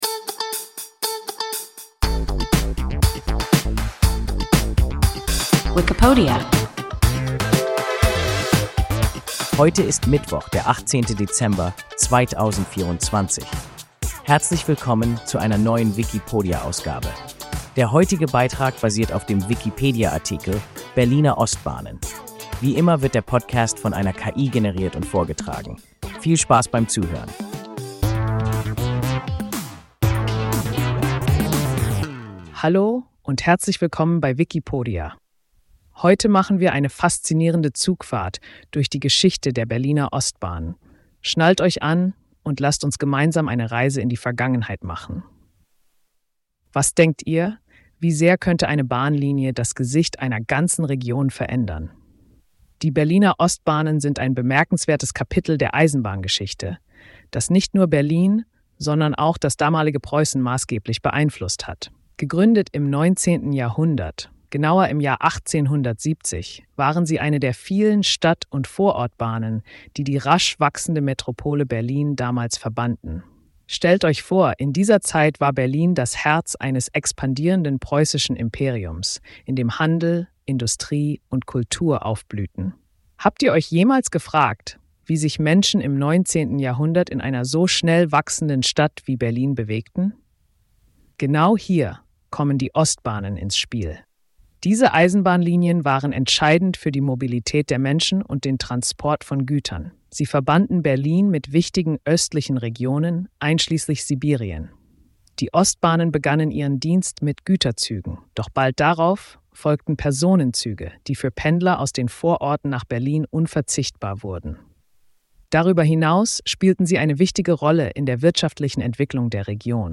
Berliner Ostbahnen – WIKIPODIA – ein KI Podcast